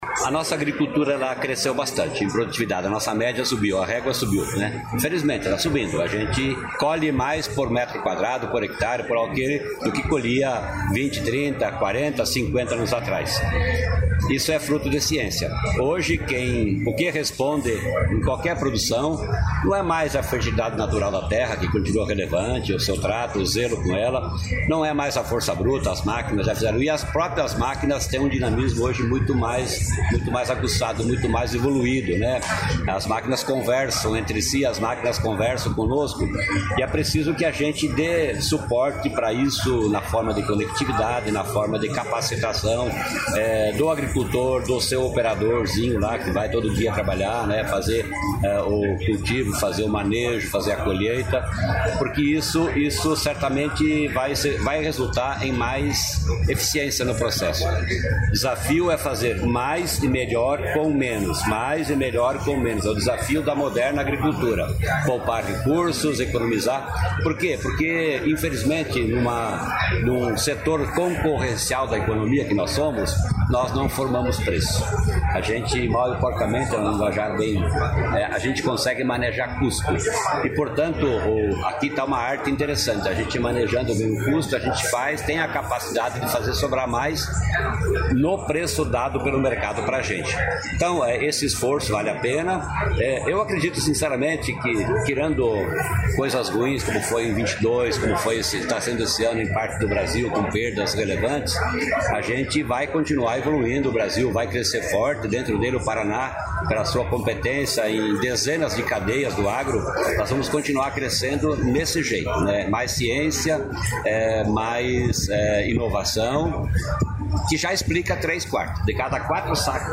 Sonora do secretário da Agricultura e Abastecimento, Norberto Ortigara, sobre inovação no agronegócio